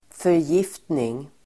Ladda ner uttalet
förgiftning substantiv, poisoning Uttal: [förj'if:tning]